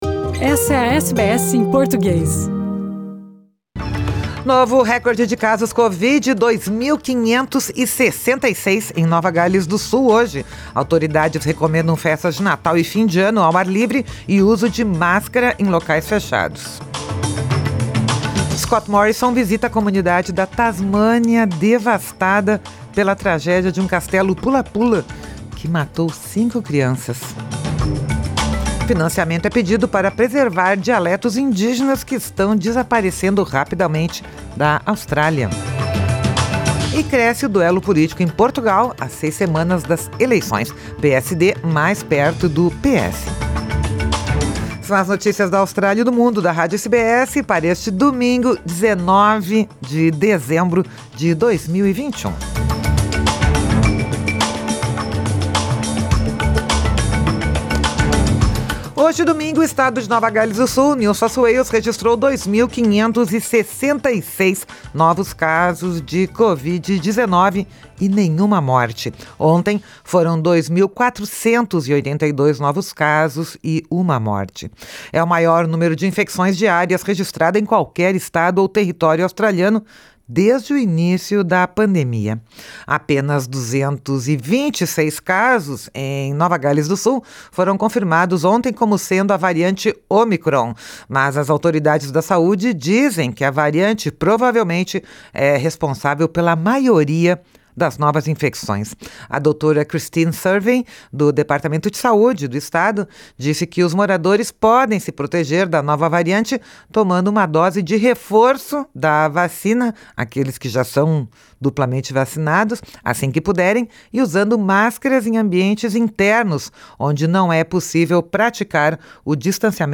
São as notícias da Austrália e do Mundo da Rádio SBS para este domingo, 19 de dezembro de 2021.